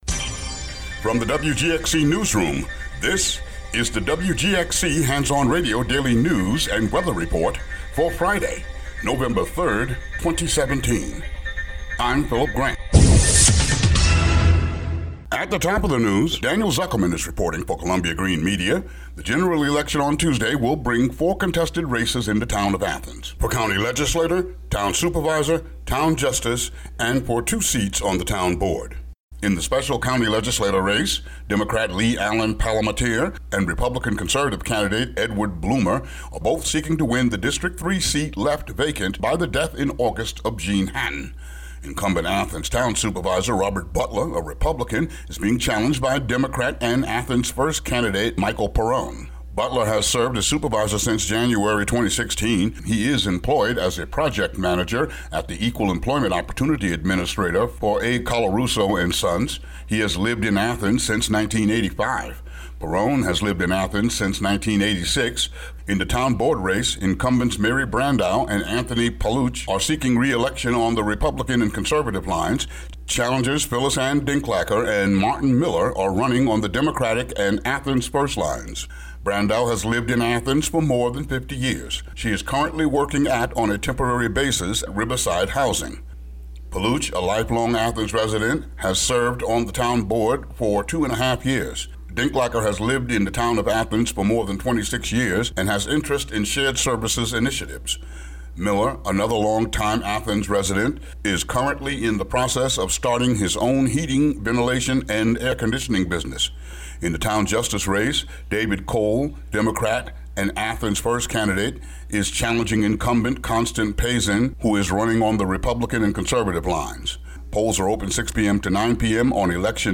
WGXC daily headlines for Nov. 3, 2017.